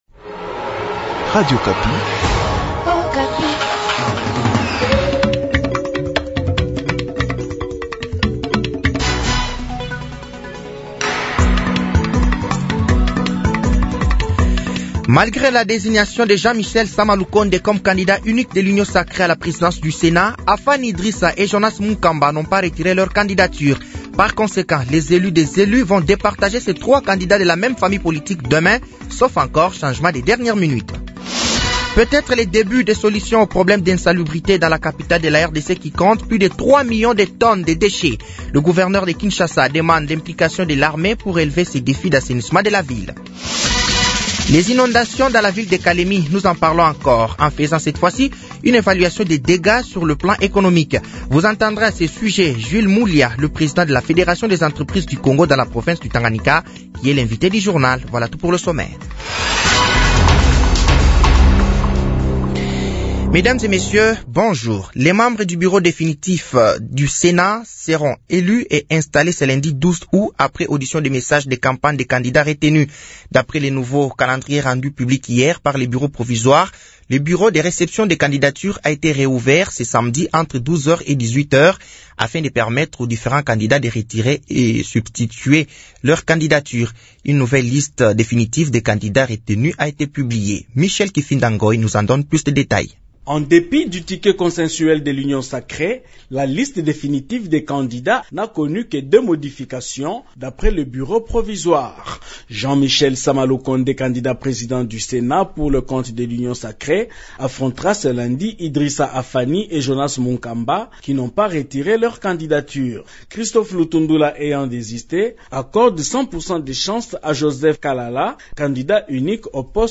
Journal français de 7h de ce dimanche 11 août 2024